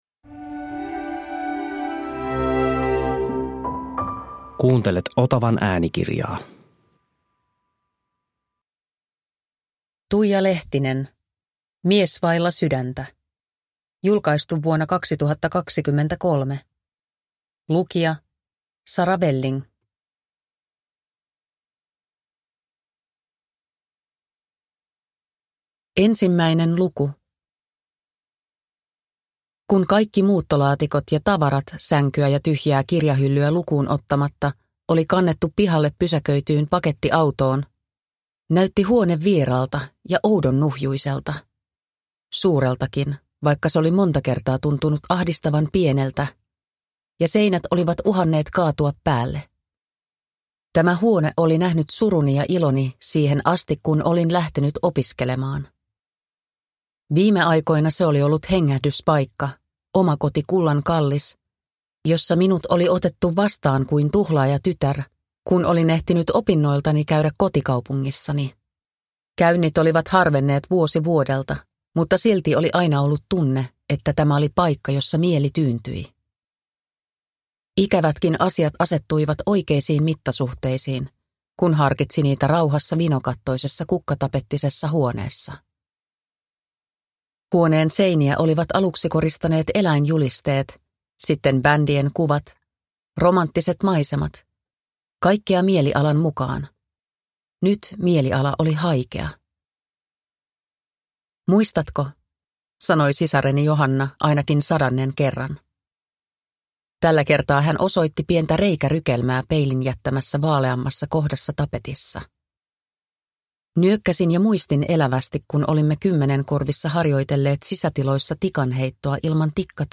Mies vailla sydäntä – Ljudbok – Laddas ner